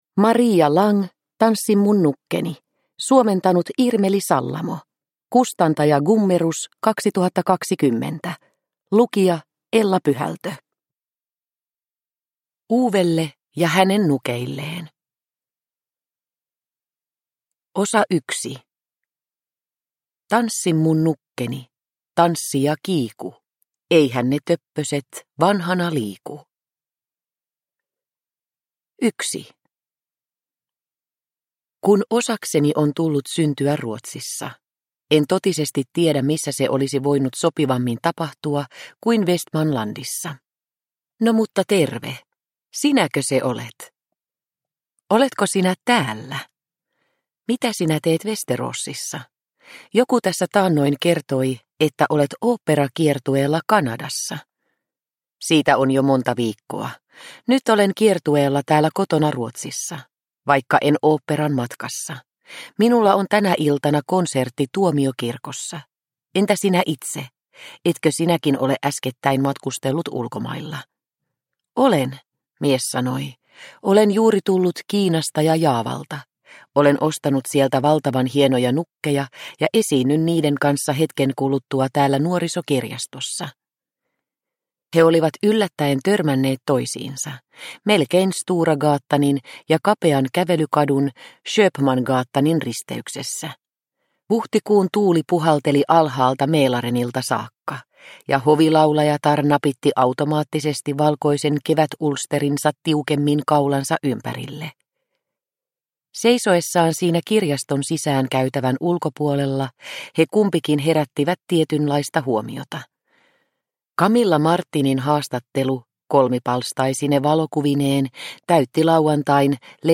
Tanssi mun nukkeni – Ljudbok – Laddas ner